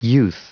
Prononciation du mot youth en anglais (fichier audio)
Prononciation du mot : youth